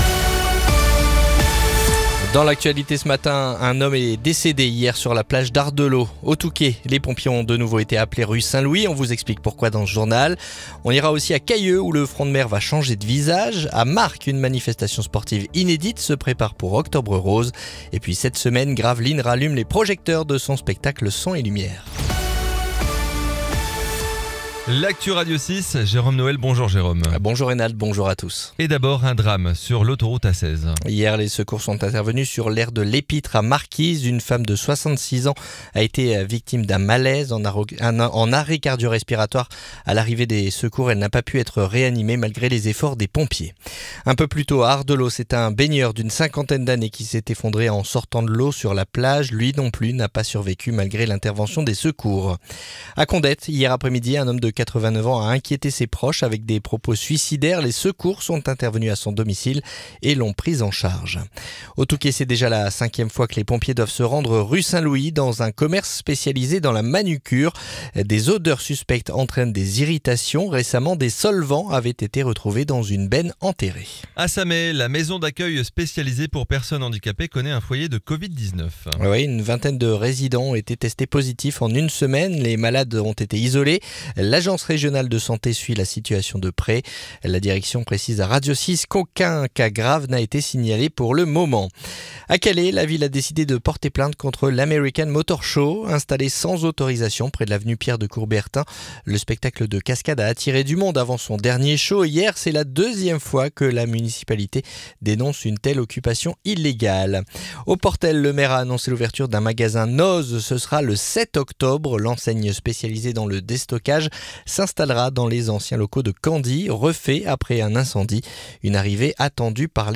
Le journal du mercredi 20 août